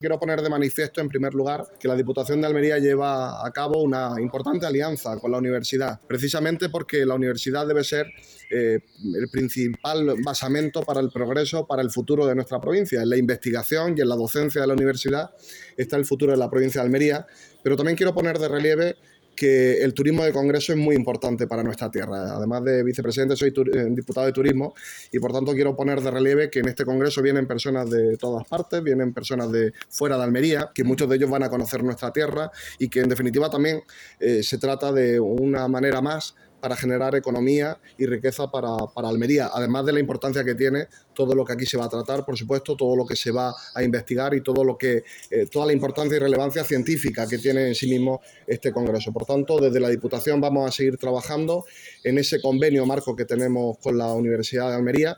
El vicepresidente, Fernando Giménez, ha dado la bienvenida a todos los participantes y expertos que analizarán las últimas tendencias, desafíos y avances en esta materia